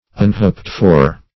Unhoped-for \Un*hoped"-for\, a.